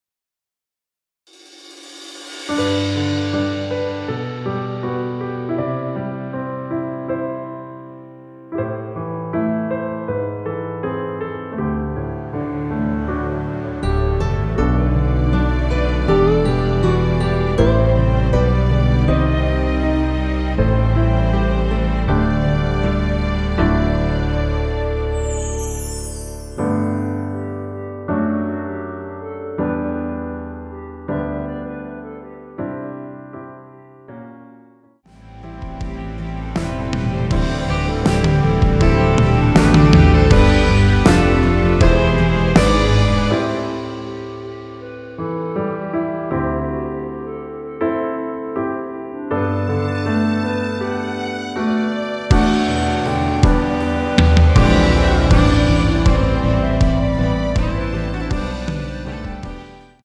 원키에서(-6)내린 멜로디 포함된 (1절+후렴) MR입니다.
앞부분30초, 뒷부분30초씩 편집해서 올려 드리고 있습니다.